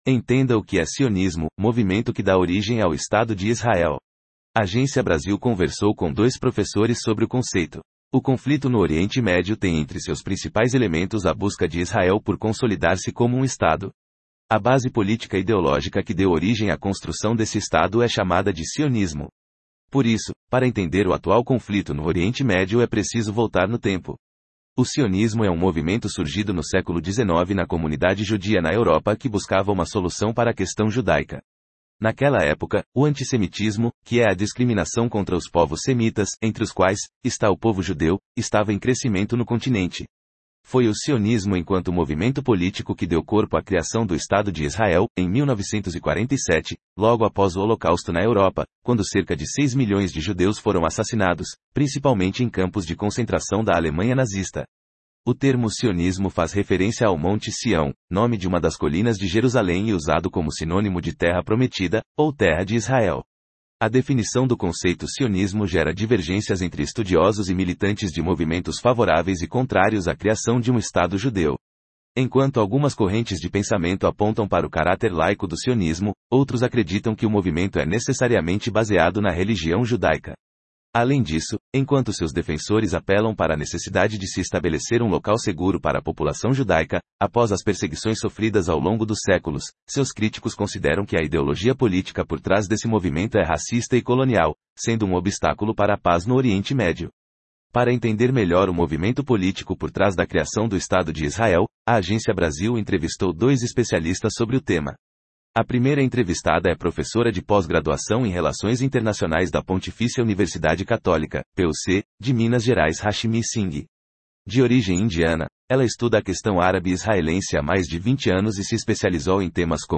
Agência Brasil conversou com dois professores sobre o conceito